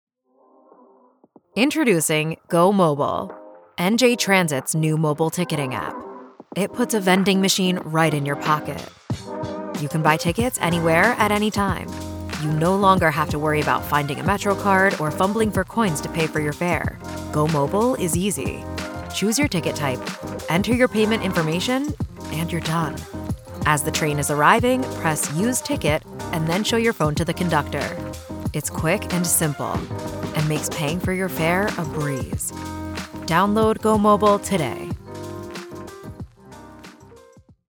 Explainer Videos
I’m an American female with a vocal style offering friendly, engaging, easy listening with clear diction. Cool and bright or warm and sunny.
Sennheiser MKH 416, Neumann TLM 103.